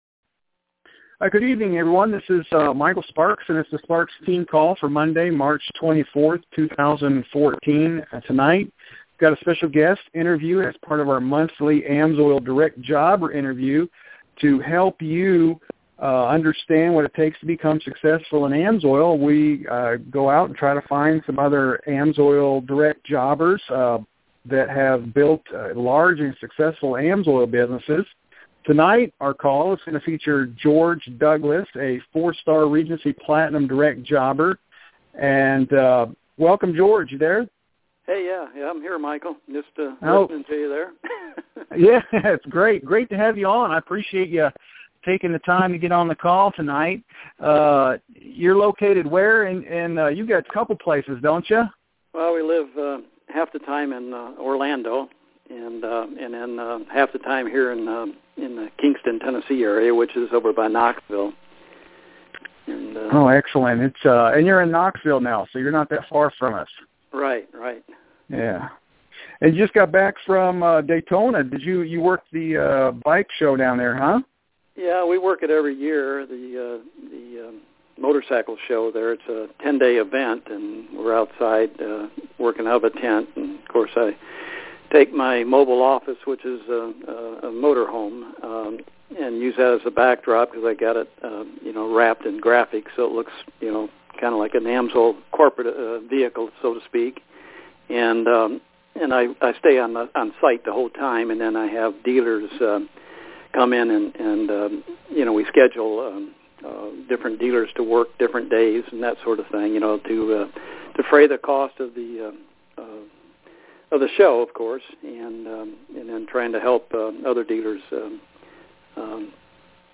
Training Call